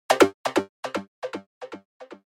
Modern Digital Alert Tone
This modern digital message notification sound features a clean, high-pitched digital chime that works great for SMS alerts, mobile notifications, and app interface sounds. It’s a simple, crisp tone that fits perfectly in smartphones, messaging apps, and modern UI designs where clear audio feedback matters.
Modern-digital-alert-tone.mp3